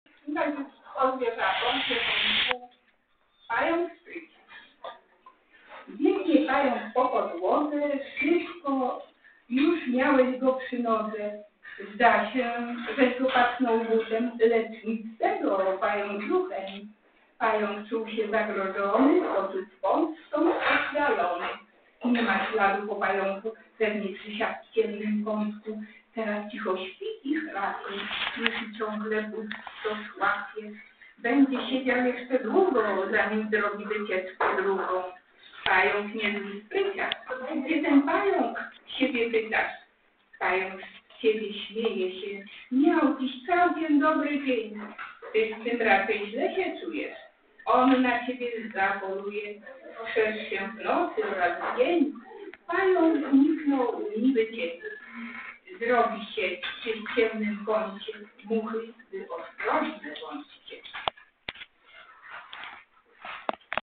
O godzinie 10. rozpoczęło się spotkanie członków i sympatyków Grupy Literackiej "Gronie".
(przrepraszamy za zakłócenia i niezbyt dobrą jakość nagrania)